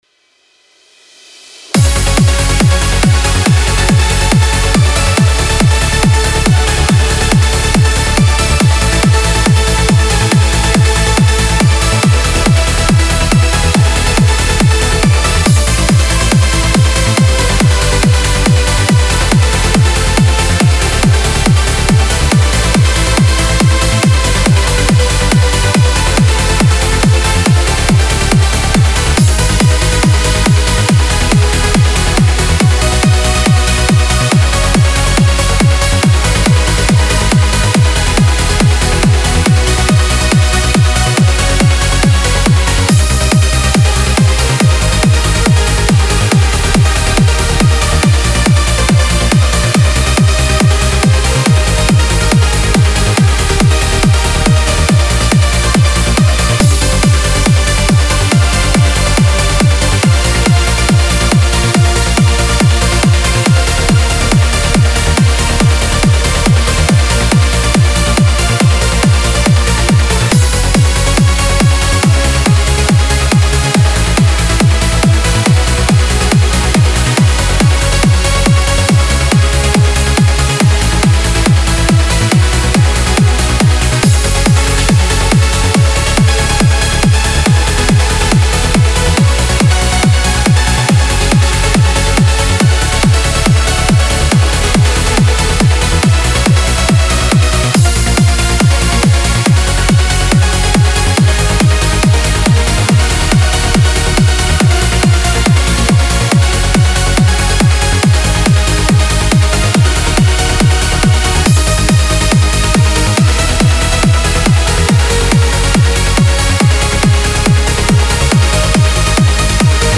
Trance Uplifting Trance
• 15 Trance Melody Kits
(Preview demo is 140 BPM)
Style: Trance, Uplifting Trance